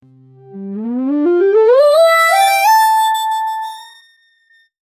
Free AI Sound Effect Generator